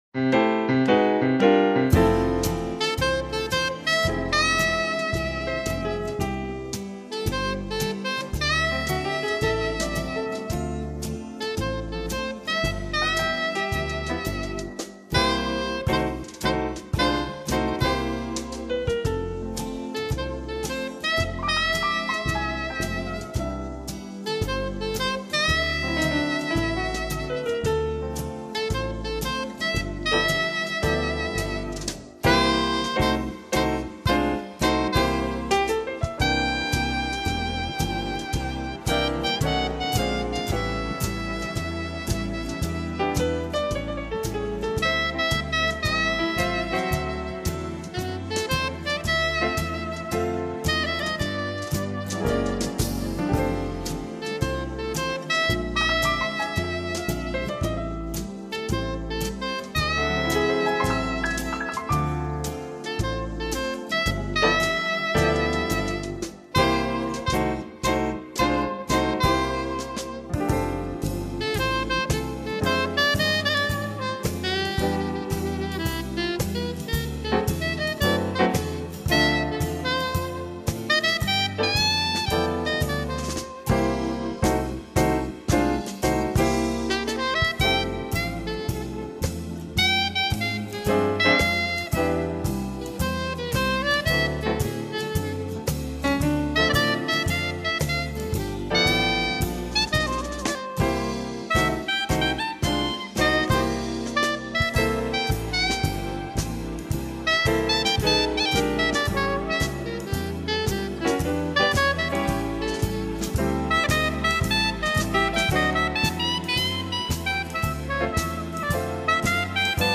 TOUT LE TALENT D’UN SAXOPHONISTE
Saxophones Sopranos :